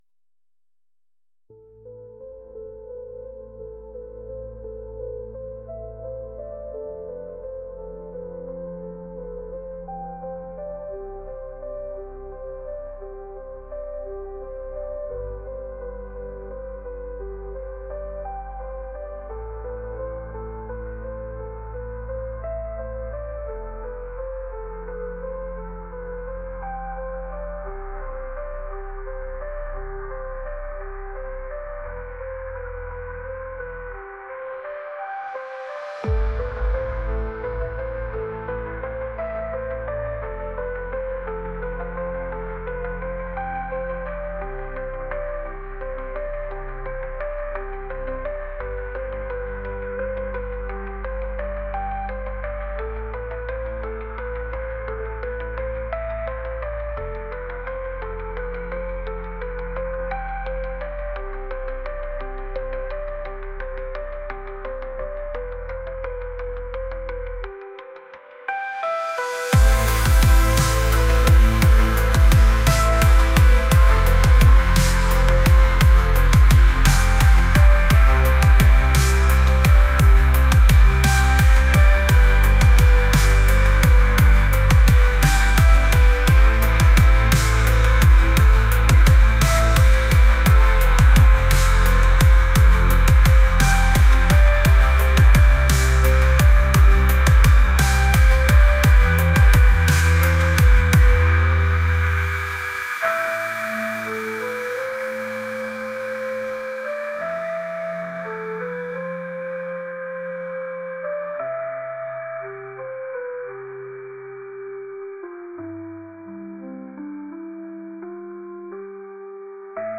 ethereal | atmospheric